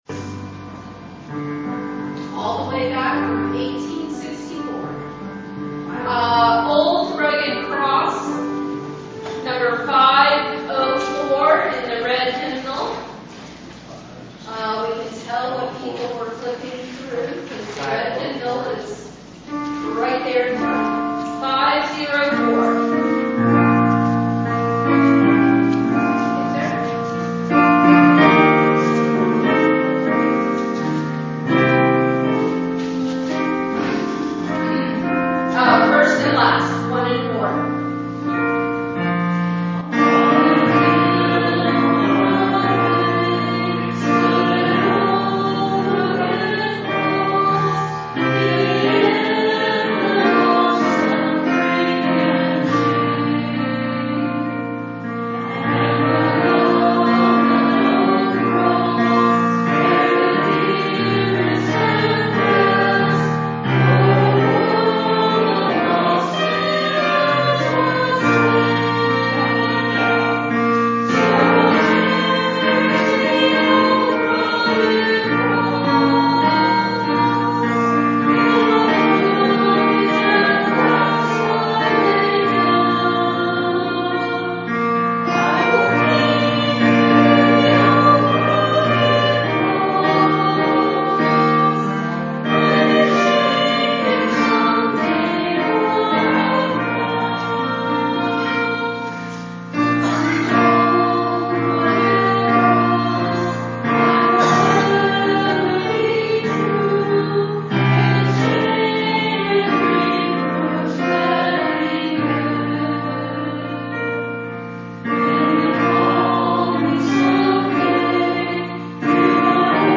Hymn Sing
It has been said that each hymn contains a sermon/message, so consider taking some time to read all of the verses to reveal the full message of the hymn...Each recording includes statements made before the hymn is sung...as well as any comments made after the singing of the preceding hymn.